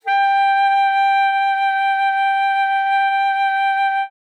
42e-sax11-g5.wav